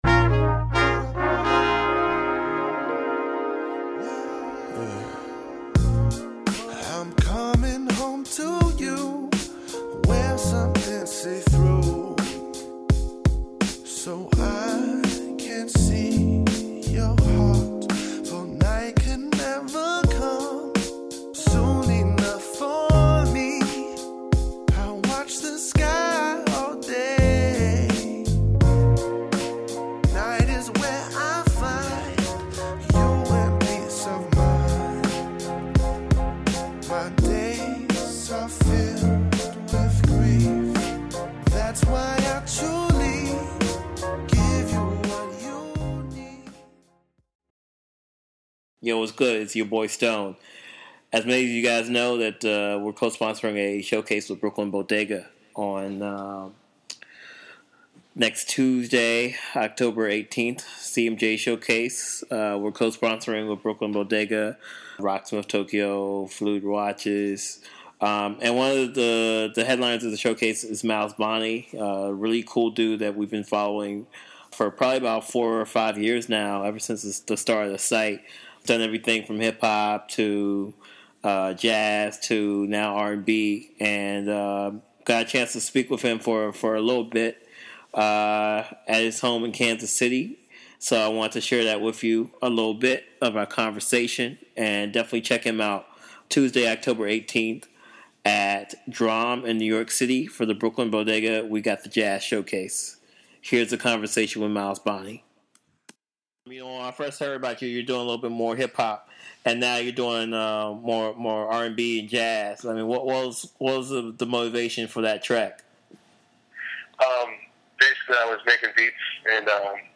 CMJ INTERVIEW